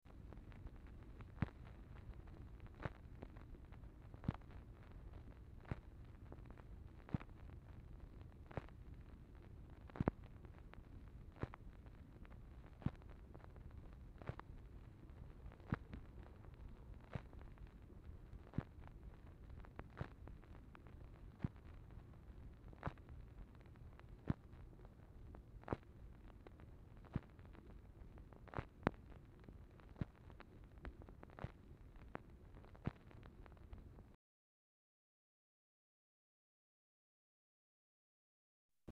Format Dictation belt
Series White House Telephone Recordings and Transcripts Speaker 2 MACHINE NOISE Specific Item Type Telephone conversation